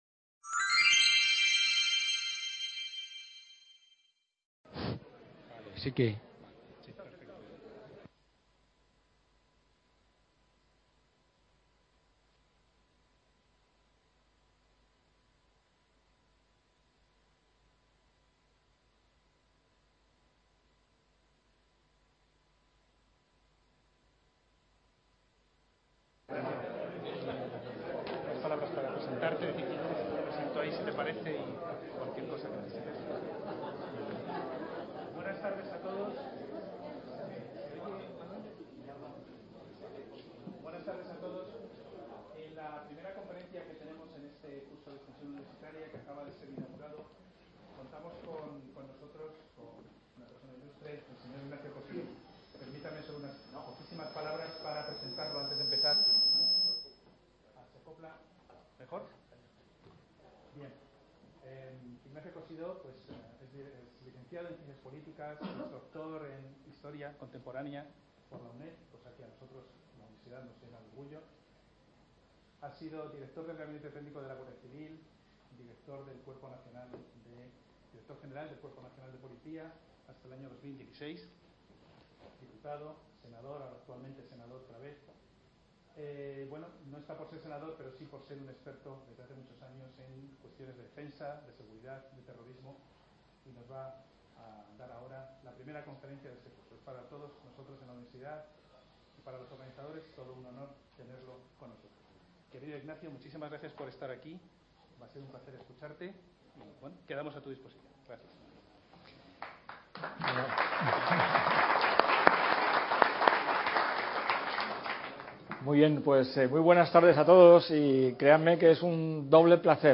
Ponencia de Ignacio Cosidó Gutiérrez en el seminario …